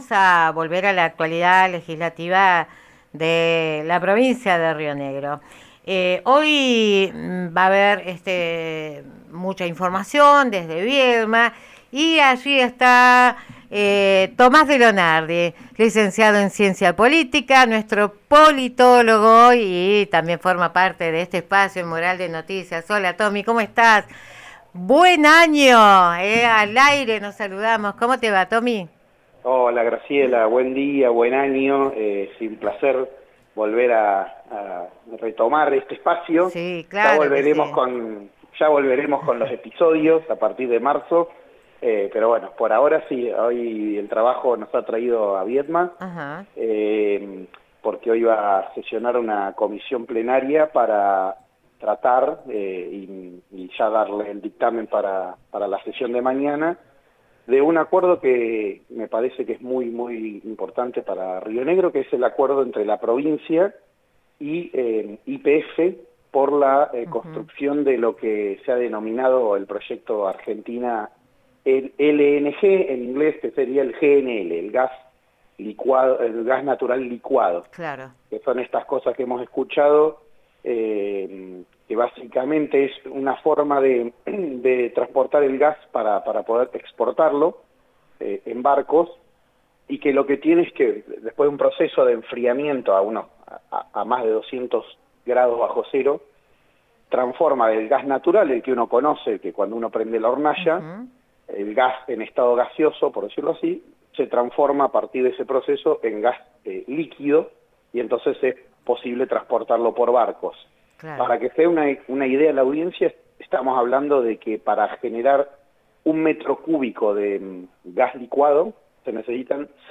En esta serie se publican las entrevistas que se hacen en informativo de La Mural
Entrevista a Lorena Matzen, legisladora UCR. 03 de marzo 2026